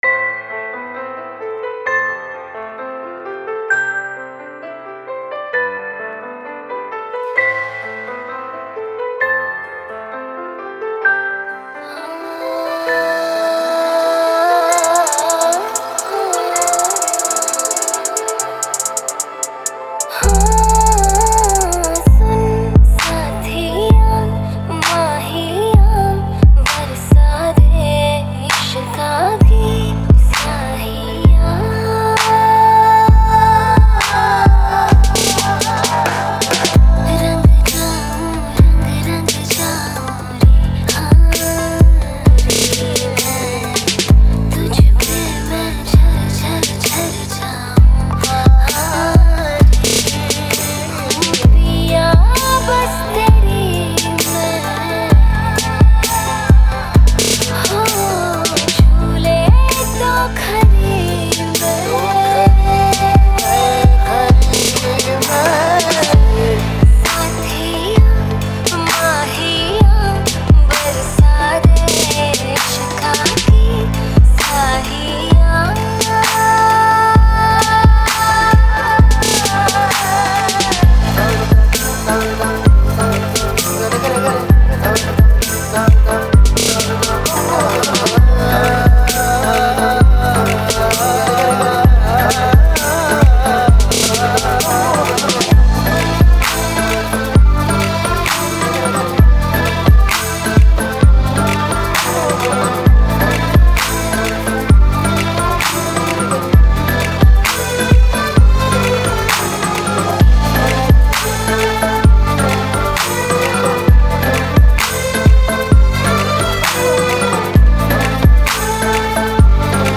DJ Remix Mp3 Songs > Single Mixes